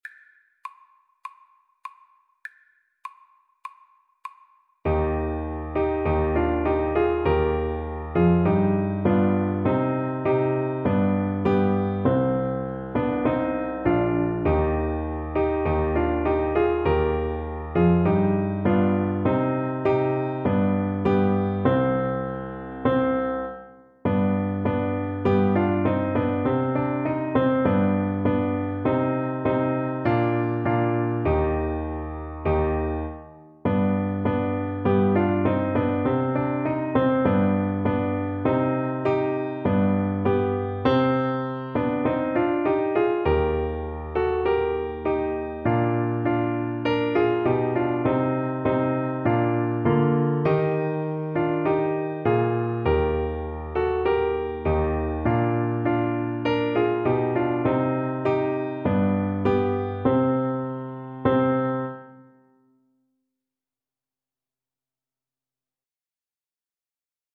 Traditional Trad. Shche ne Vmerla Ukrayiny ni Slava, ni volya (Ukrainian National Anthem) Alto Saxophone version
Alto Saxophone
Eb major (Sounding Pitch) C major (Alto Saxophone in Eb) (View more Eb major Music for Saxophone )
4/4 (View more 4/4 Music)
Traditional (View more Traditional Saxophone Music)